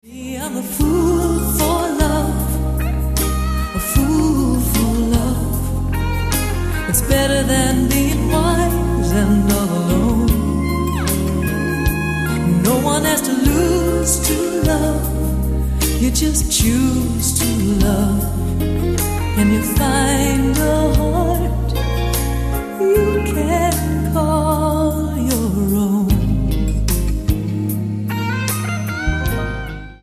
Genre: Female Vocals
vocals
Keyboards
Guitar
Background Vocals